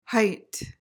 PRONUNCIATION: (hyt) MEANING: adjective: Named or called.